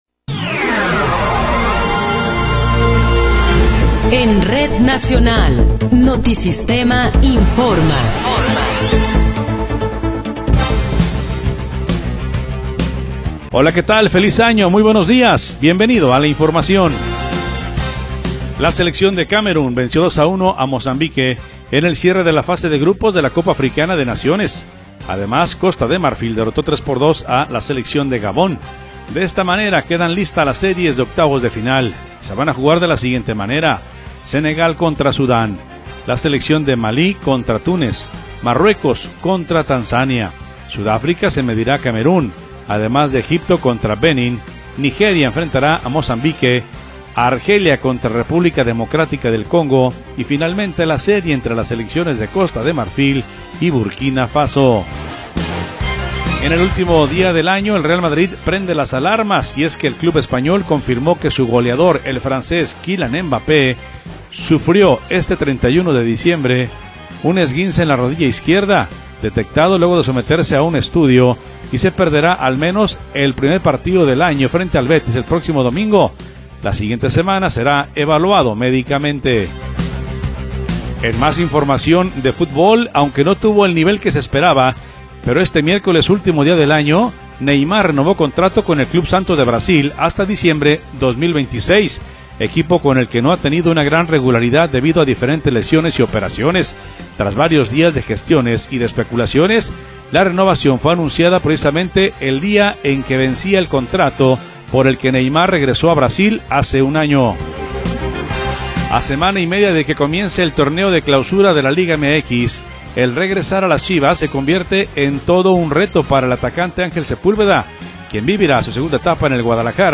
Noticiero 8 hrs. – 1 de Enero de 2026